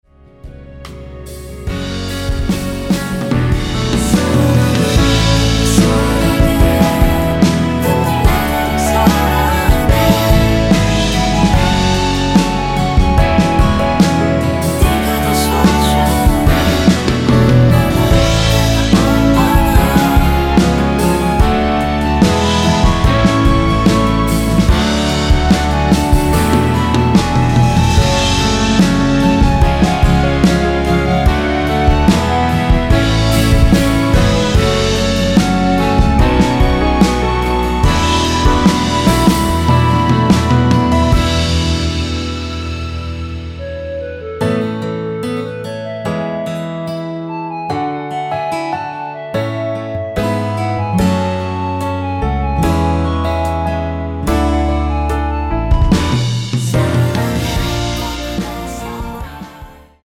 원키에서(-1)내린 멜로디와 코러스 포함된 MR입니다.(미리듣기 확인)
Gb
앞부분30초, 뒷부분30초씩 편집해서 올려 드리고 있습니다.